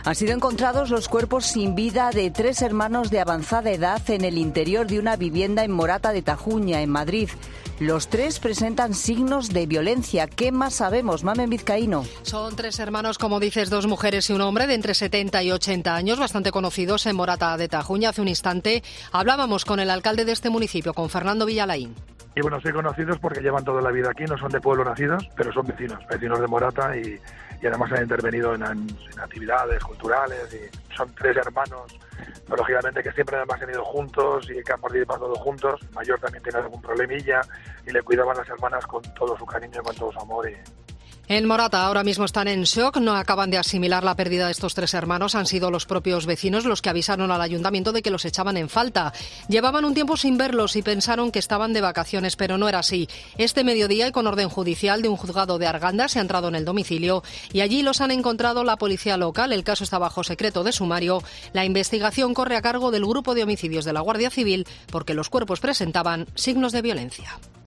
El alcalde de Morata de Tajuña habla en COPE